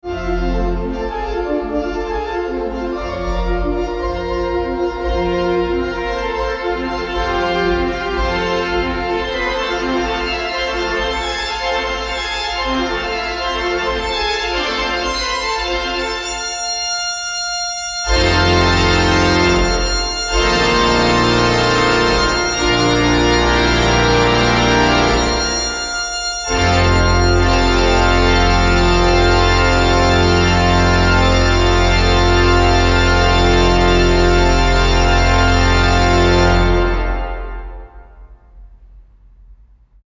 We pray, hear sermons, sing hymns, and enjoy music provided by our choir,  soloists and accompanied by our world-class Von Beckerath Pipe organ (we promise not to blow you away, but it does include 2,640 pipes and is one of only 6 in the US.